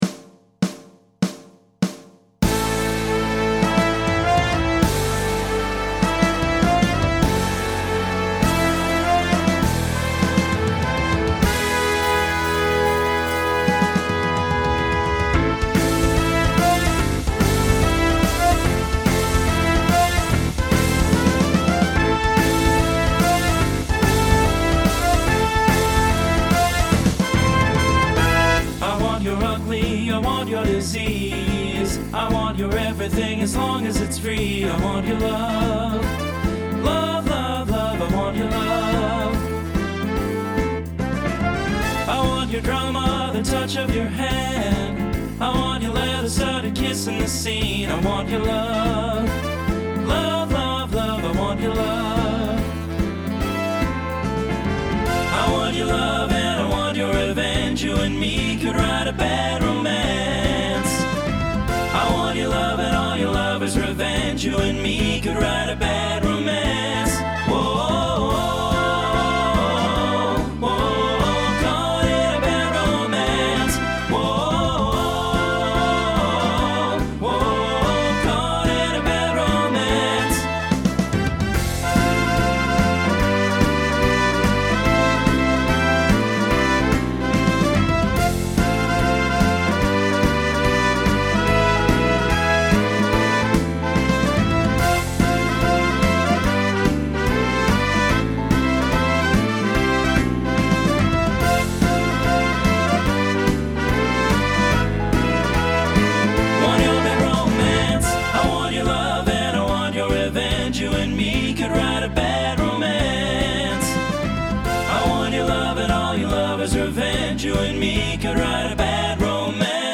Pop/Dance , Swing/Jazz
Transition Voicing Mixed